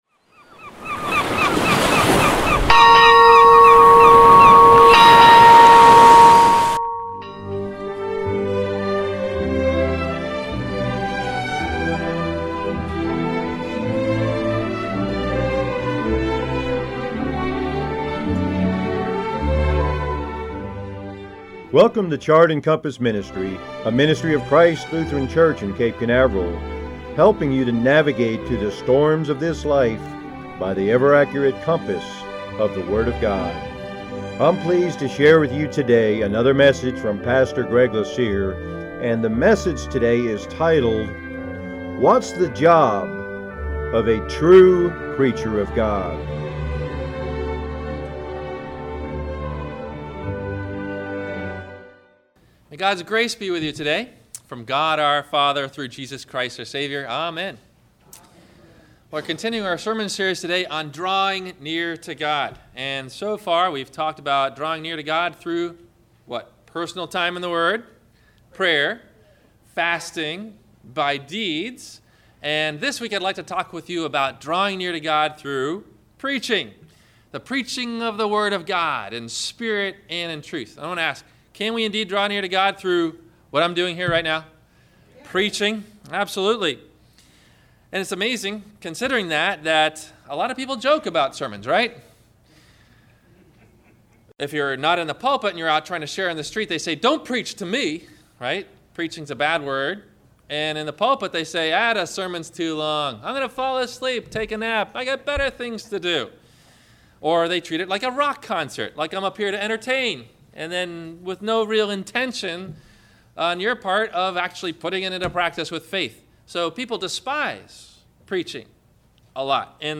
- WMIE Radio Sermon – April 20 2015 - Christ Lutheran Cape Canaveral